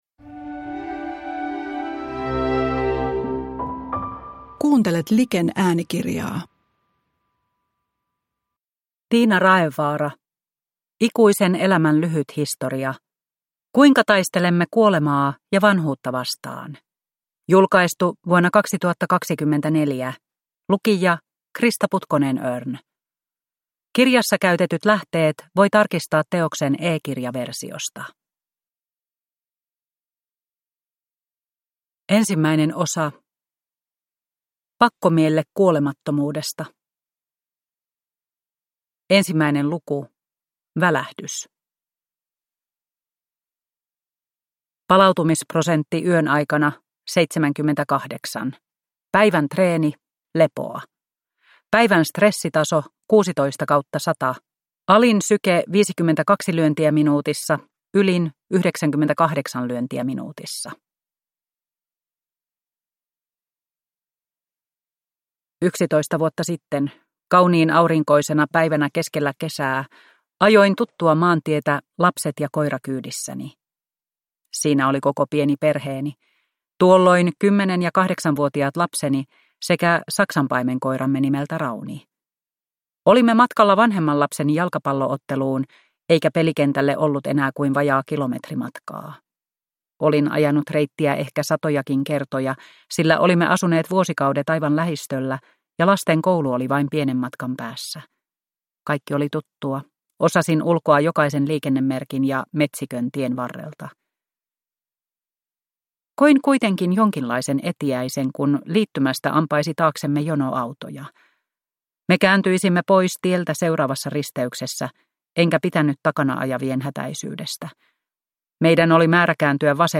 Ikuisen elämän lyhyt historia – Ljudbok